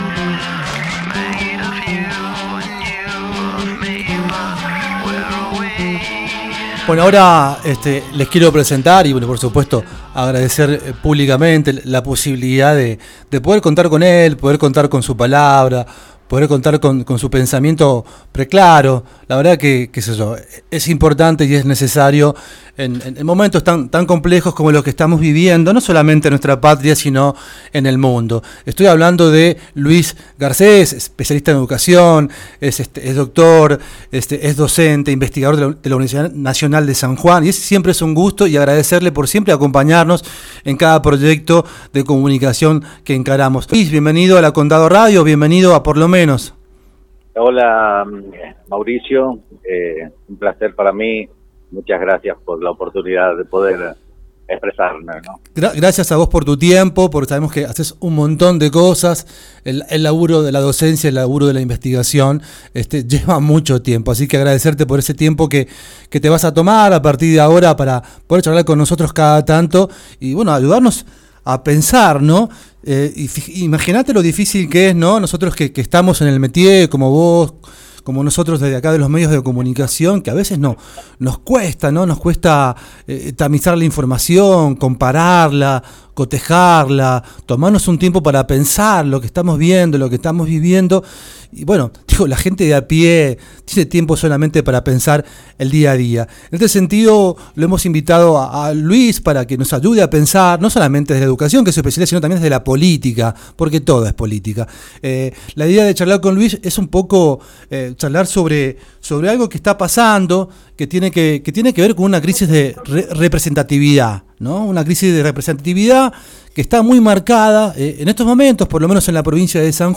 Condado Radio, entrevistado